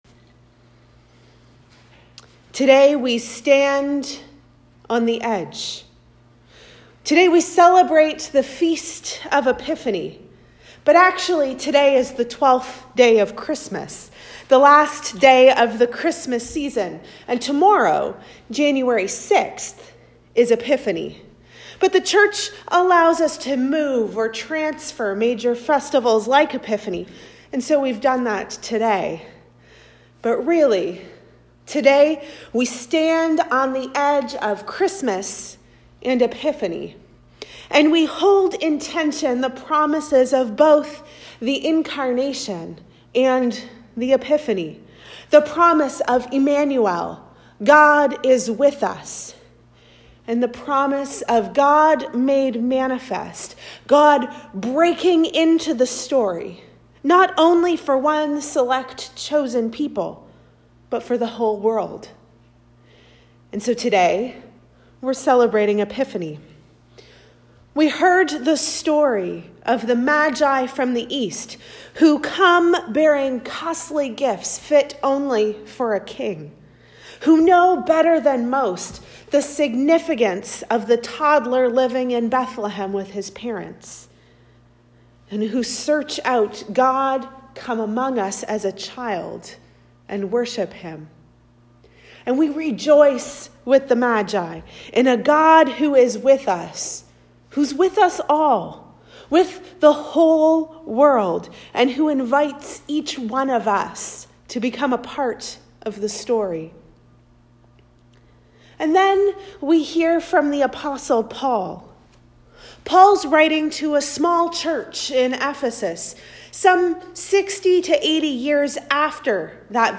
Sermons | Parish of the Valley
Recorded at St Augustine's, Beachburg